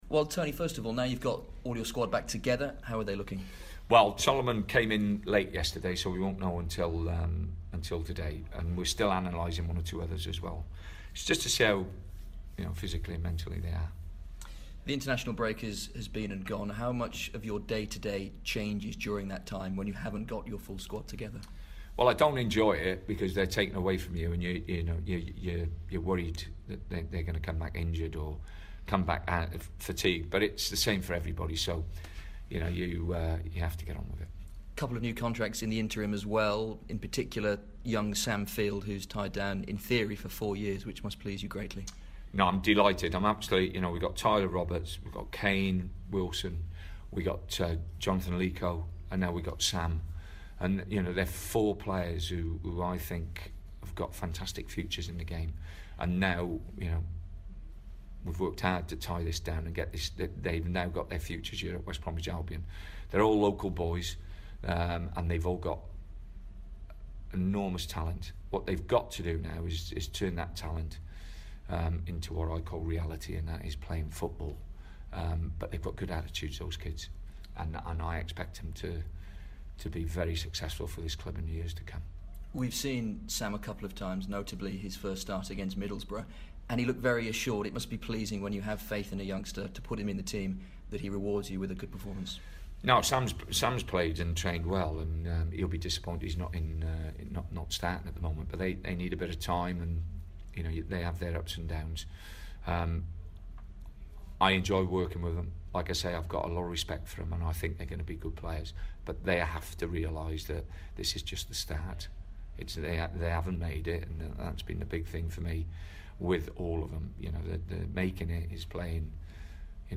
West Brom boss Tony Pulis speaks to BBC WM about their Premier League game against Tottenham...